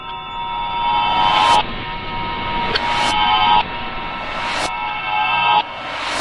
Здесь вы найдете эффекты телепортации, хронопрыжков, искривления пространства-времени и других фантастических явлений.
Путешествие во времени назад кинематографический эффект